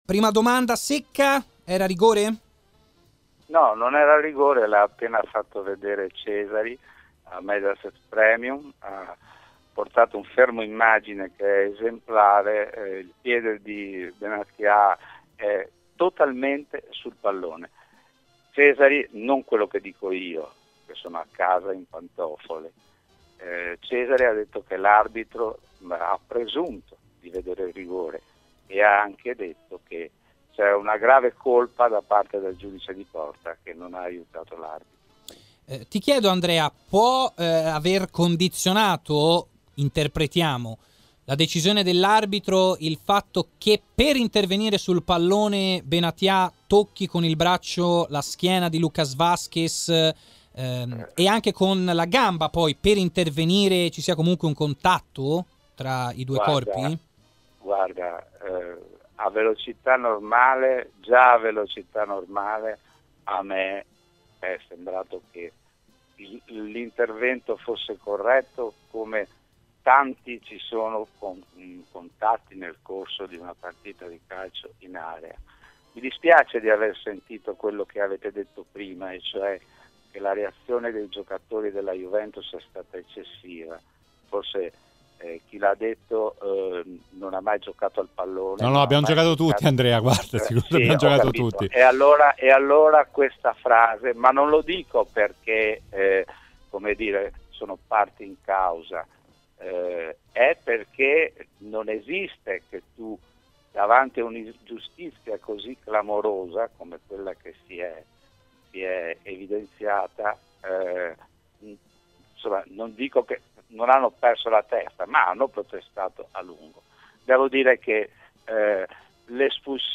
durante il Live Show su RMC Sport ha commentato così la partita tra Real Madrid e Juventus e l'episodio del fallo su Lucas Vázquez
In studio